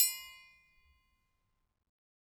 Triangle6-Hit_v2_rr1_Sum.wav